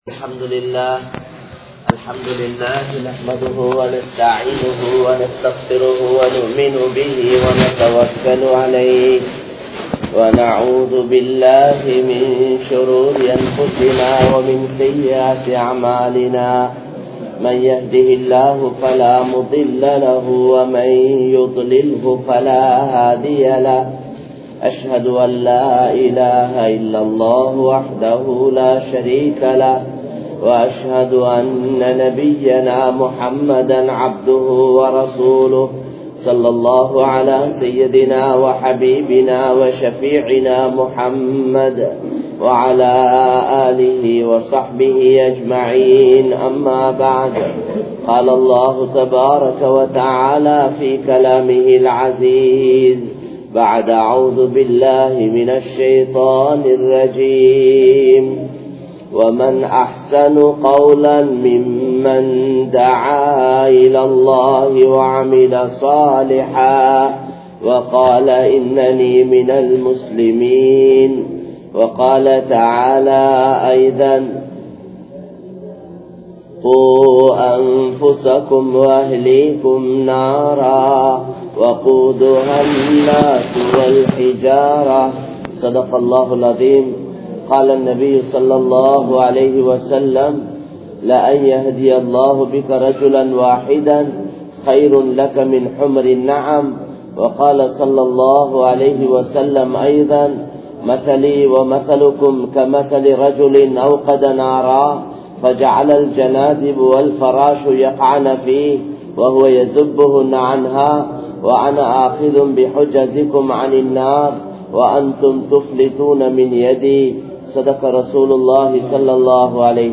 Thawaththin Noakkam (தஃவத்தின் நோக்கம்) | Audio Bayans | All Ceylon Muslim Youth Community | Addalaichenai